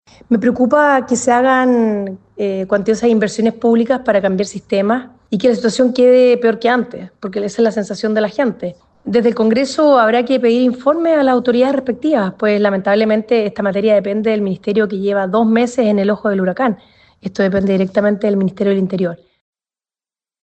Finalmente, la diputada independiente de la bancada republicana, Sofía Cid, expresó su preocupación ante esta crisis y dejó en claro que se debe realizar el llamado al ministerio del Interior.